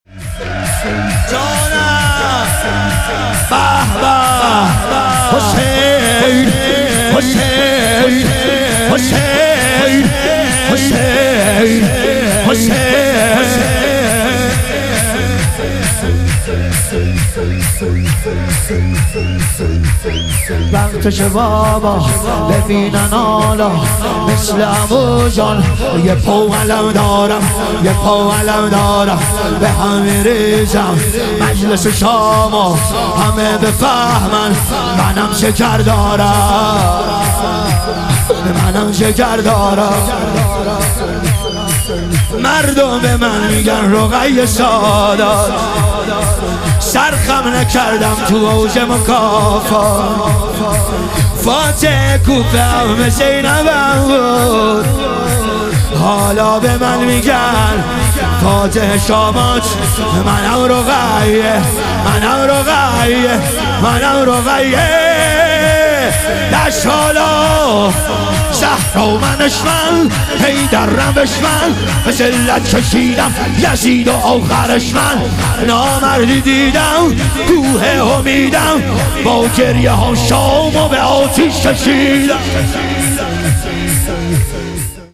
ظهور وجود مقدس حضرت رقیه علیها سلام - شور
شب ظهور وجود مقدس حضرت رقیه علیها سلام